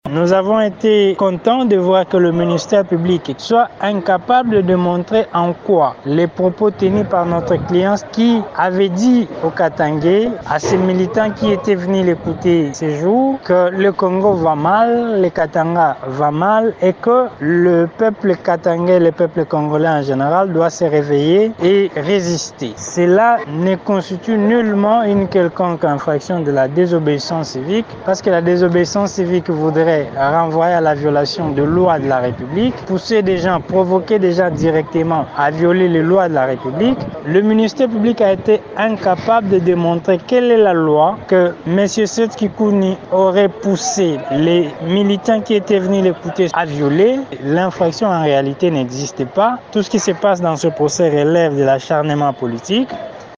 Cette audience publique s'est déroulée en audience foraine à la prison centrale de Makala.